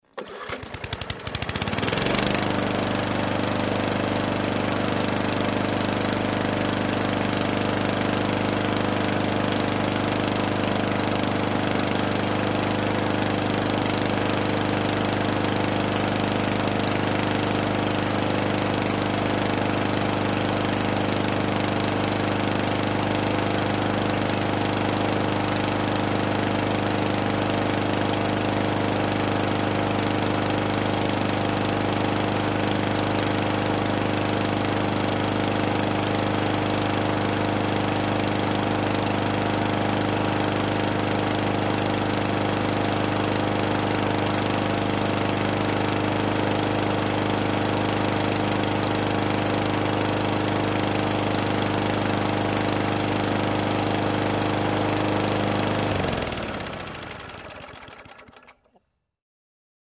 Звуки травы
Кто-то стрижет траву газонокосилкой